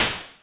sound_attack_2.wav